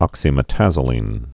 (ŏksē-mĭ-tăzə-lēn, -mĕtə-zō-)